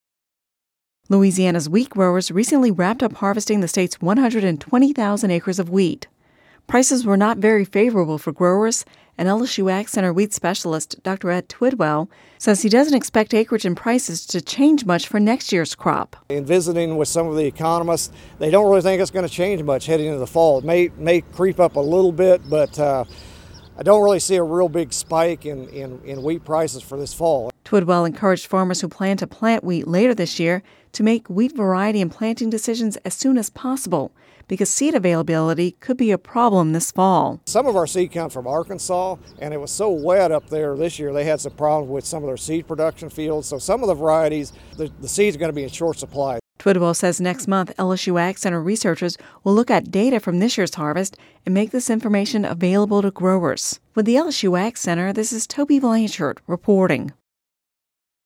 (Radio News 06/07/10) Louisiana wheat growers recently wrapped up harvesting the state’s 120,000 acres of wheat.